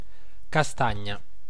Ääntäminen
Synonyymit marrone Ääntäminen Tuntematon aksentti: IPA: /ka.ˈstaɲ.ɲa/ IPA: /ka.ˈsta.ɲa/ Haettu sana löytyi näillä lähdekielillä: italia Käännös 1. kaŝtano Suku: f .